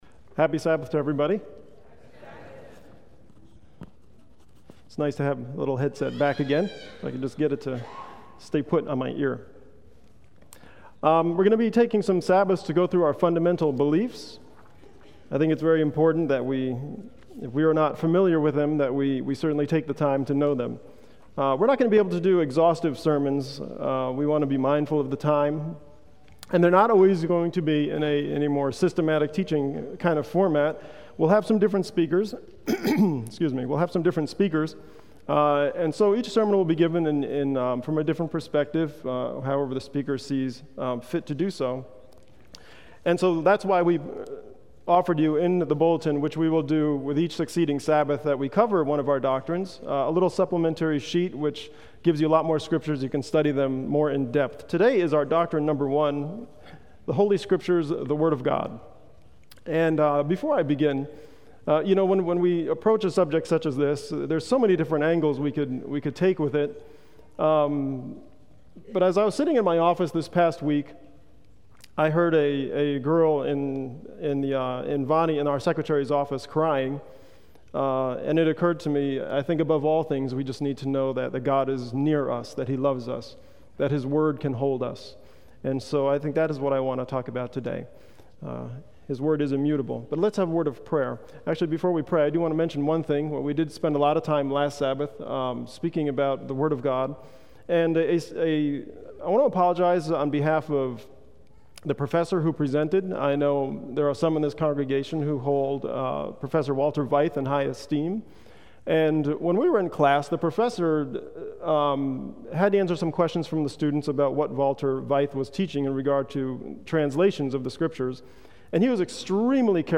on 2016-01-23 - Sabbath Sermons